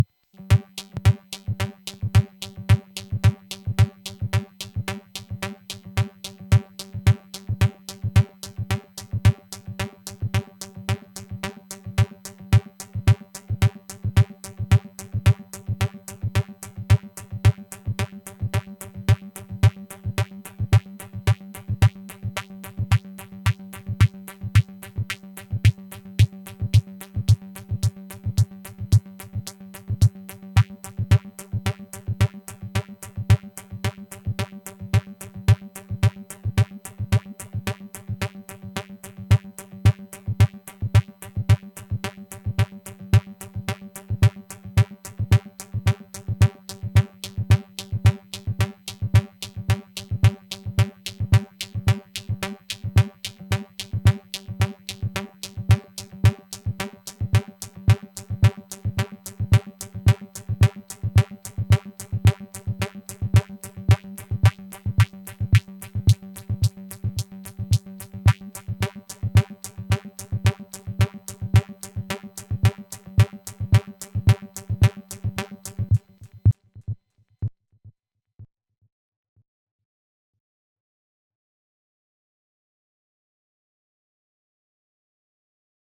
In due secondi posso trovarmi una cassa che segue un ritmo con addirittura la dinamica, cambiano le forme d’onda dell’oscillatore
Per aver usato un solo oscillatore, un filtro un inviluppo e un LFO il risultato è interessante.
Caricati sulla DAW, le ho sincronizzate e aggiunto un po’ di delay all’una e all’altra.